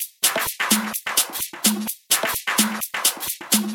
VEH1 Fx Loops 128 BPM
VEH1 FX Loop - 33.wav